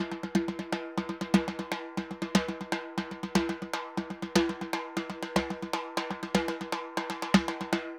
Timba_Baion 120_2.wav